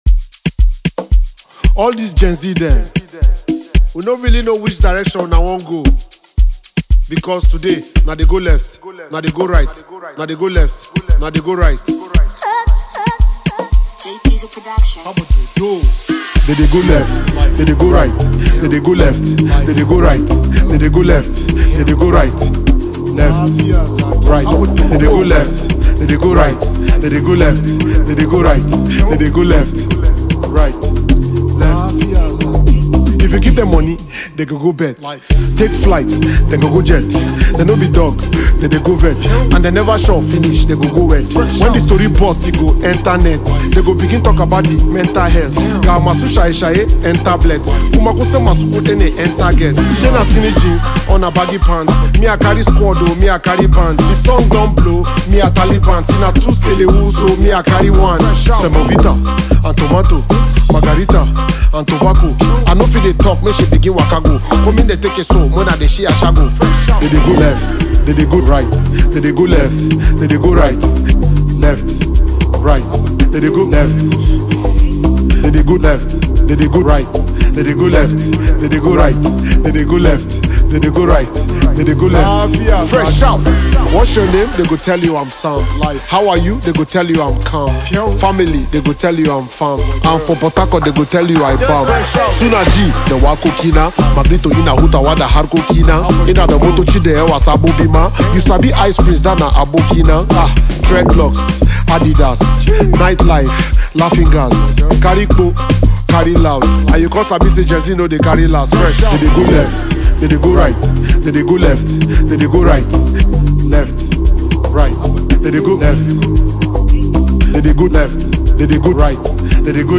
Nigerian singer and rapper